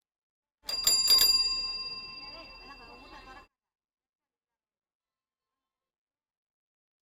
Bell cycle Cycle Ding Ring Ting sound effect free sound royalty free Sound Effects